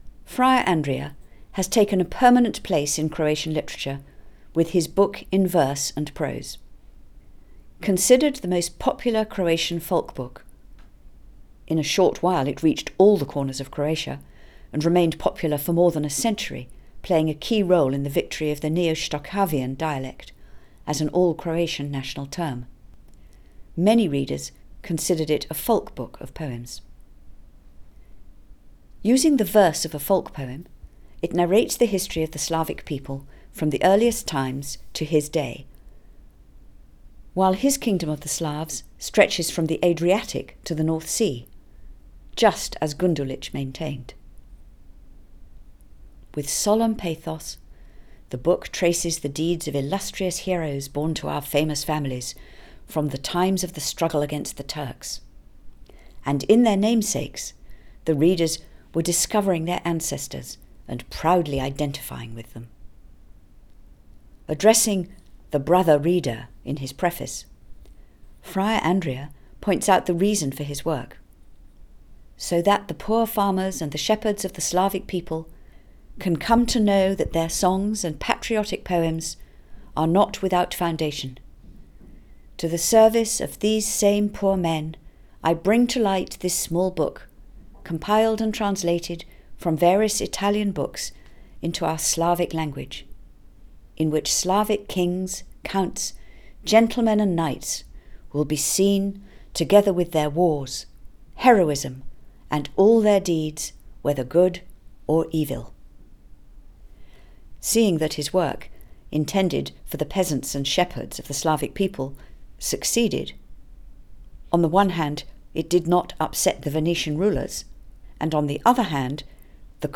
Pleasant Coversation of Slavic People